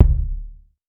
TC Kick 27.wav